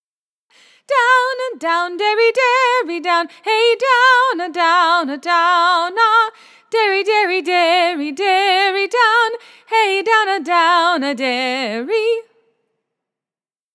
Refrain of stanza 1 of “The Countrey Lasse”